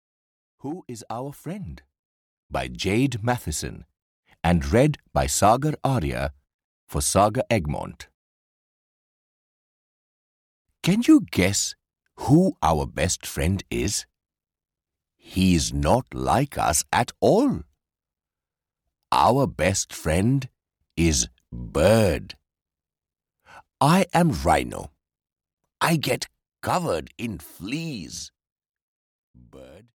Who is Our Friend (EN) audiokniha
Ukázka z knihy